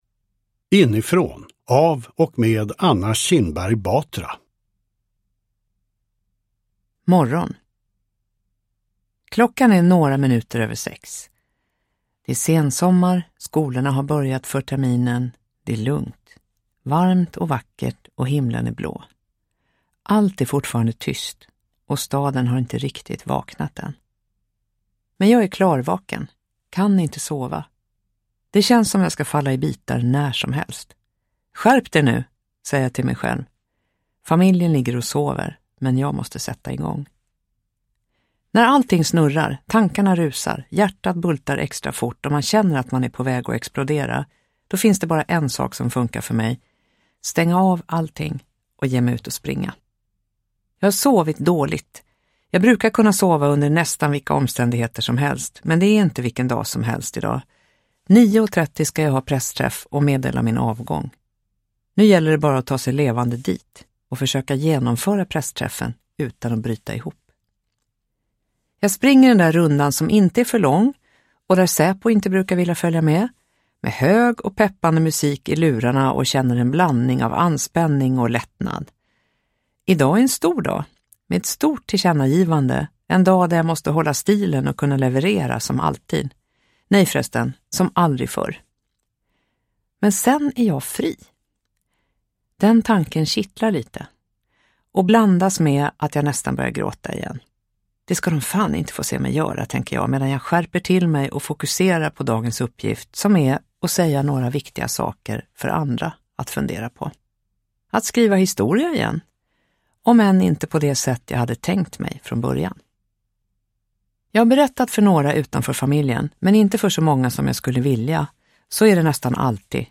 Uppläsare: Anna Kinberg Batra
Ljudbok